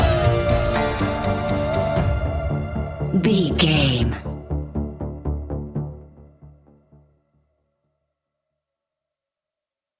Aeolian/Minor
ominous
dark
haunting
eerie
electric guitar
drums
synthesiser
horror music